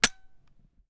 NeedReload.wav